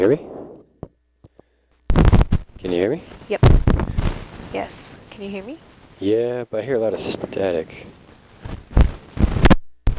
Functions of Bookended Narrow-Pitch-Range Regions
2. Complaining